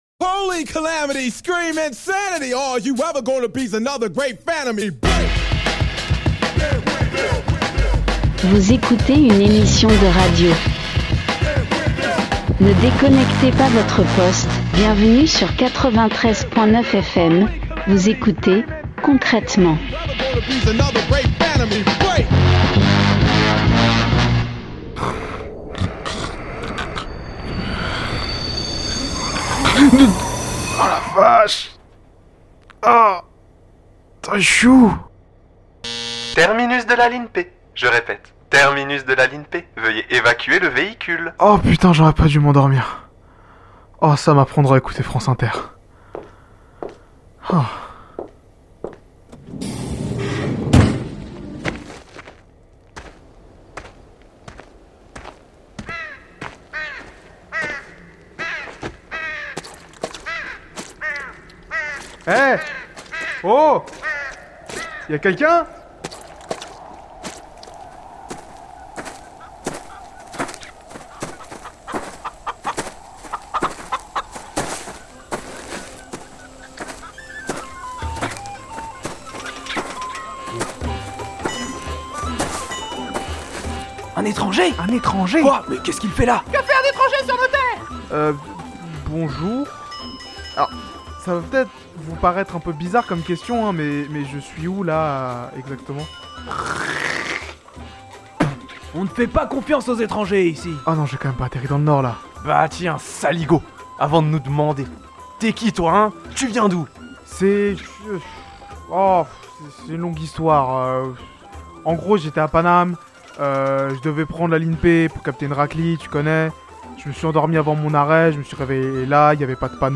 Partager Type Création sonore Société mercredi 9 novembre 2022 Lire Pause Télécharger La Seine et Marne est le New Jersey français.